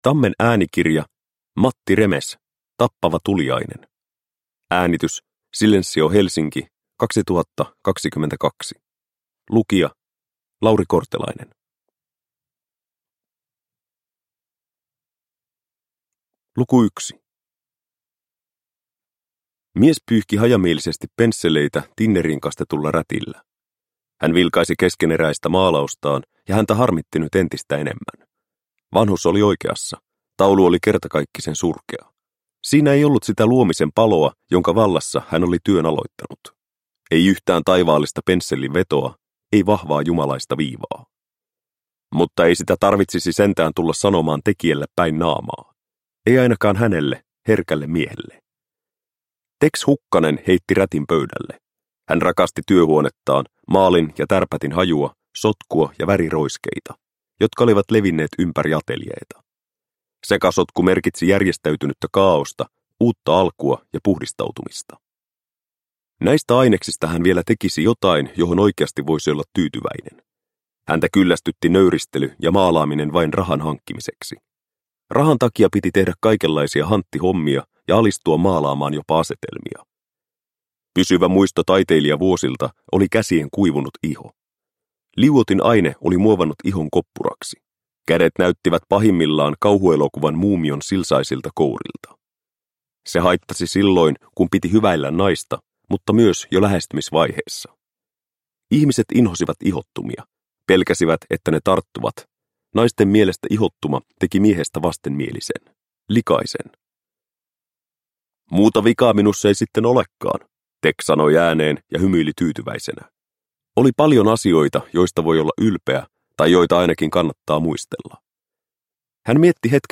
Tappava tuliainen – Ljudbok – Laddas ner